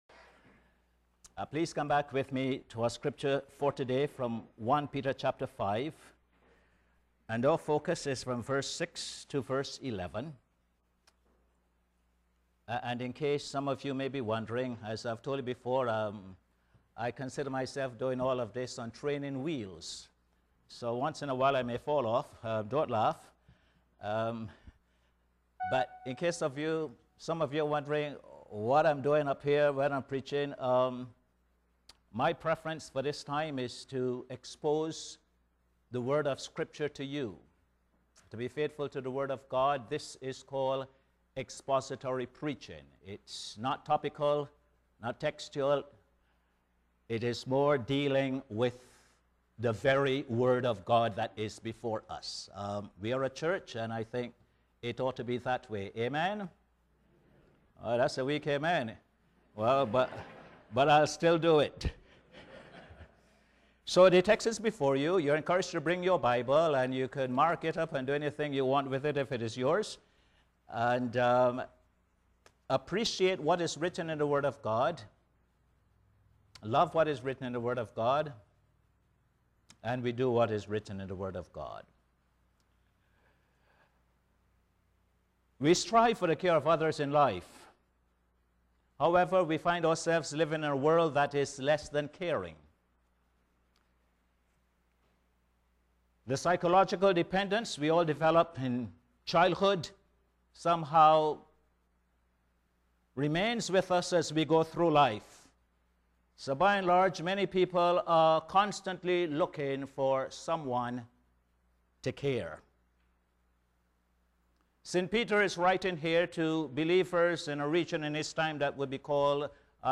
Posted in Sermons on 25.